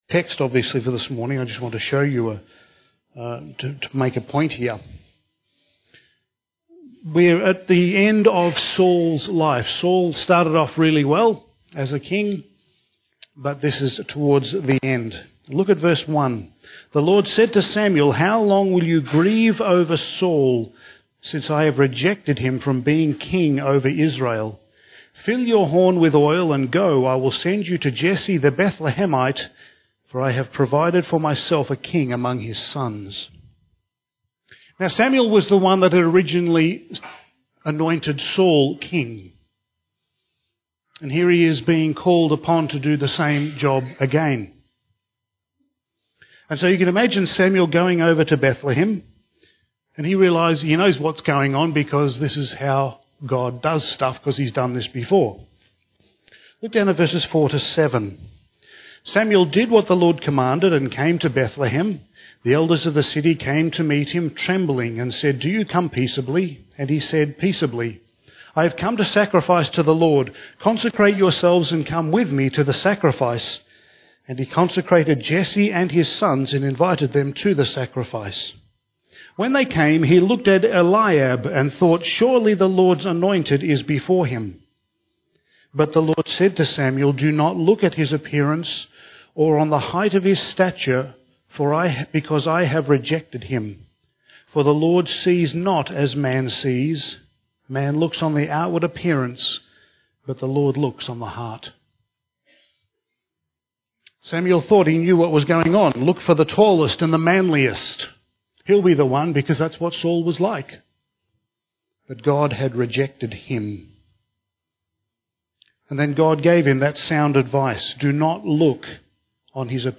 Passage: Judges 6:25-7:15 Service Type: Sunday Morning